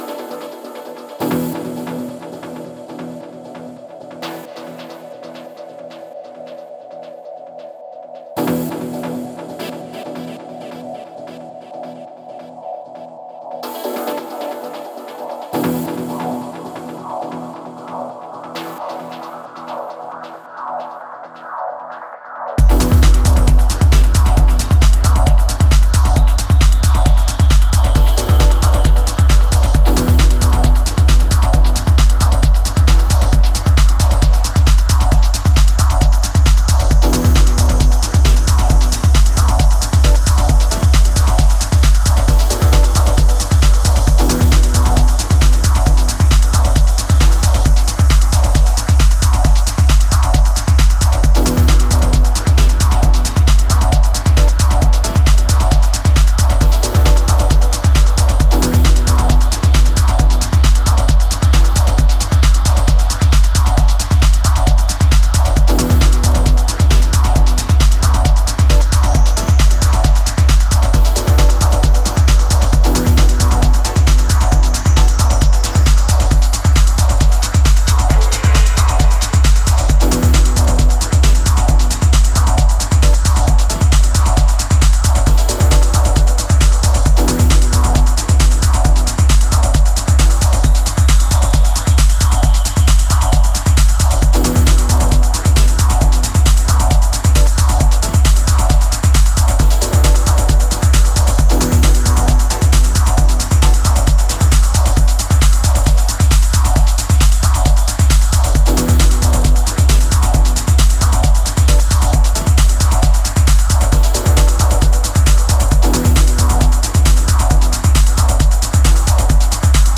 A split EP pairing two euphoric dance anthems
Romantic, ecstatic, and full of <3